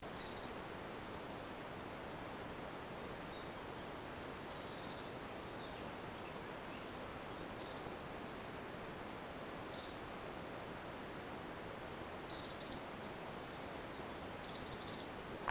c'è un uccellino nascosto tra cespugli ed arbusti, non si fa quasi vedere ma si fa sentire con questi 'tocchi' anche a poca distanza.
Siamo in Toscana, agosto 2012 a 300 m.
Scricciolo ?
Anche a me sembrava dal trillo ma, intravedendolo, forse anche sterpazzolina o occhiocotto ?